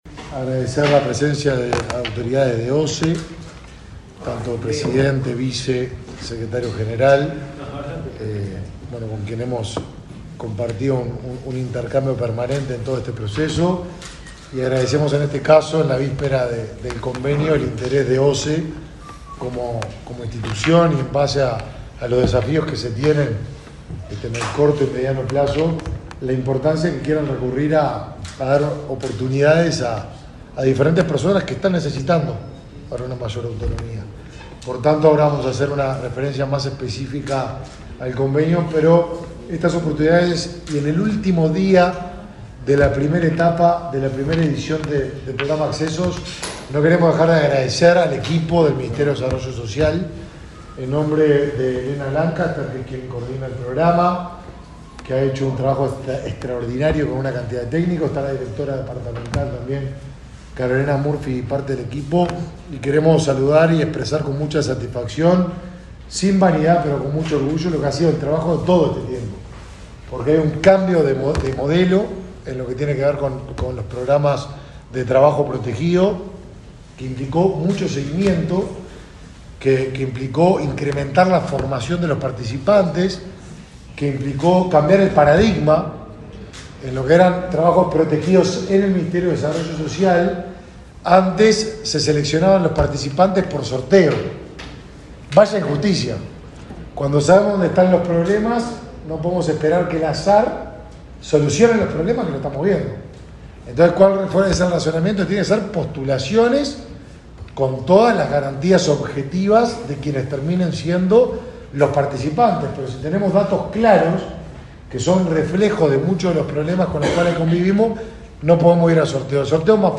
Palabras de autoridades en convenio entre Mides y OSE
El ministro de Desarrollo Social, Martín Lema; el presidente de la OSE, Raúl Montero, y la vicepresidenta del organismo, Susana Montaner, destacaron